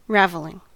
Ääntäminen
Vaihtoehtoiset kirjoitusmuodot ravelling Ääntäminen US Haettu sana löytyi näillä lähdekielillä: englanti Käännöksiä ei löytynyt valitulle kohdekielelle.